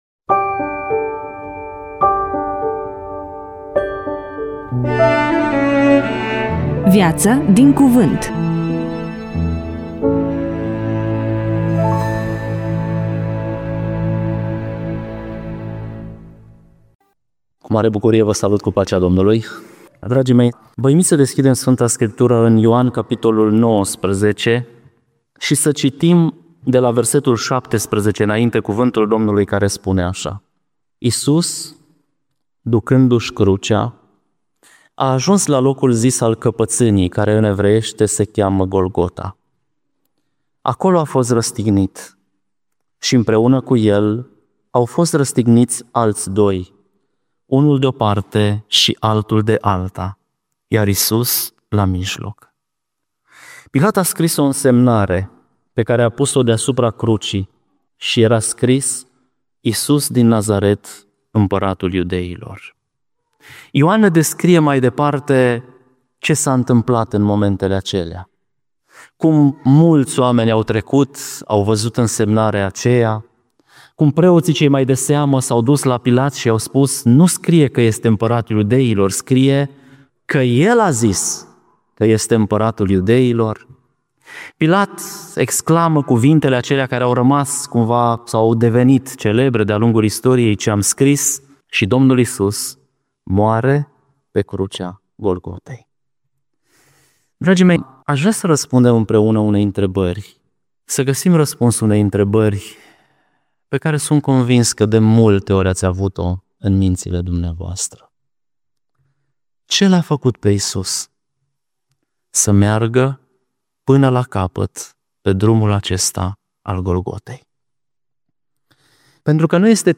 EMISIUNEA: Predică DATA INREGISTRARII: 11.04.2026 VIZUALIZARI: 3